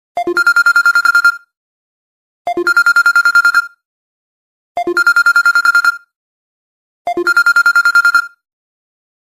스사모 - 벨소리 / 알림음
Samsung basic bell
samsung_basic_bell.mp3